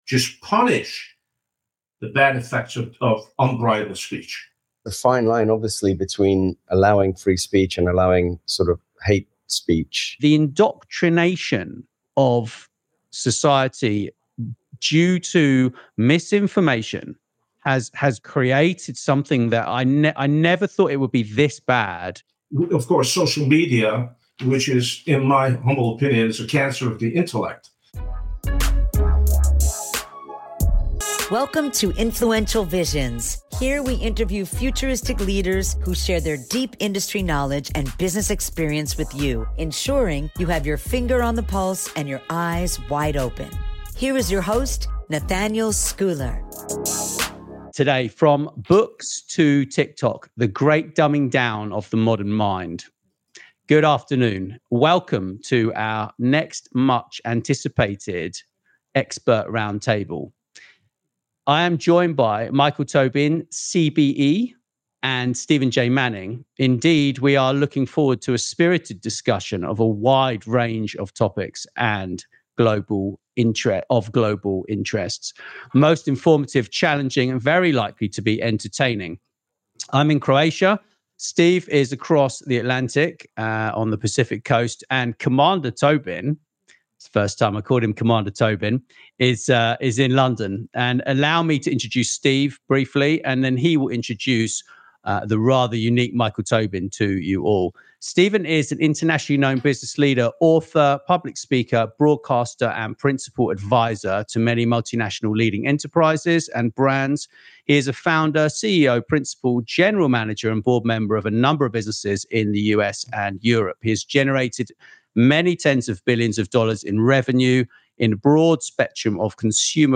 Broadcasting from London, Rijeka (Croatia) and Los Angeles, this exclusive roundtable brings together global business leaders and visionaries to explore the impact of technology, media, and culture on the way we think, learn, and connect. Don’t miss this dynamic conversation filled with insight, inspiration, and a touch of humor.